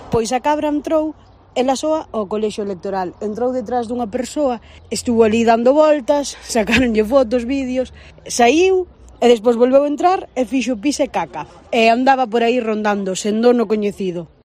La tiktoker